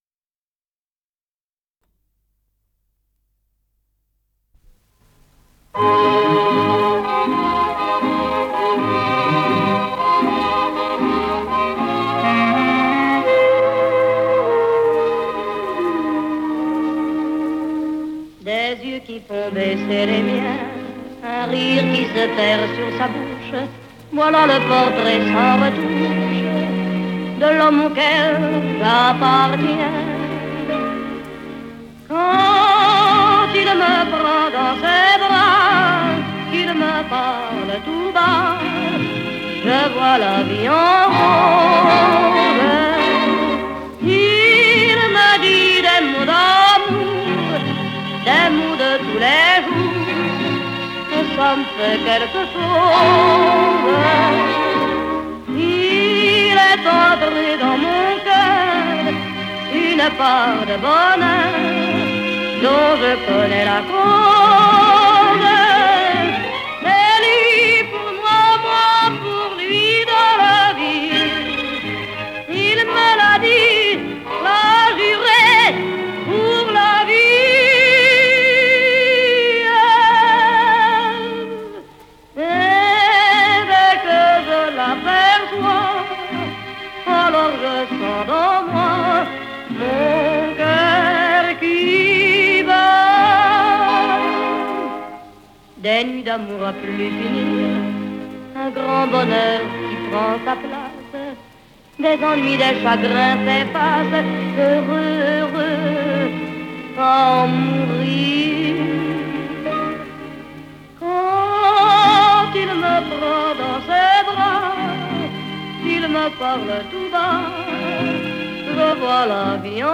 с профессиональной магнитной ленты
АккомпаниментОркестр
Скорость ленты38 см/с
Тип лентыORWO Typ 106